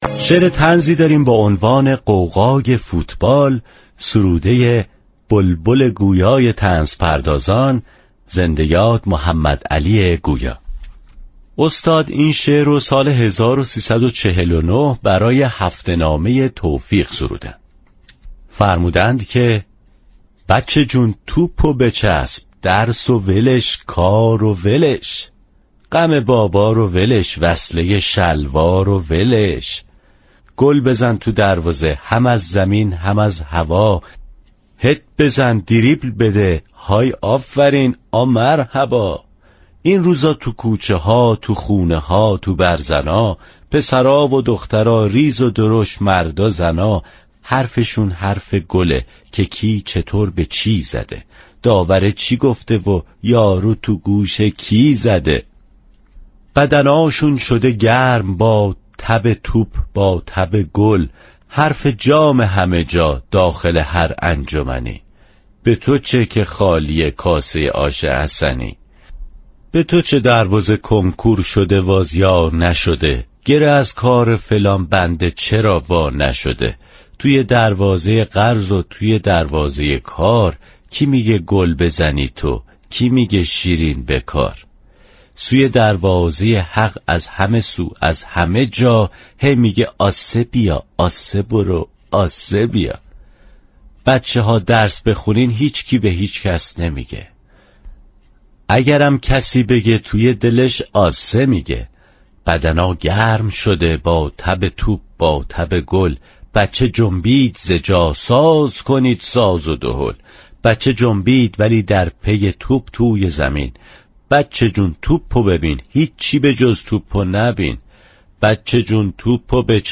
شعر طنز